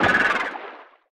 Sfx_creature_symbiote_death_01.ogg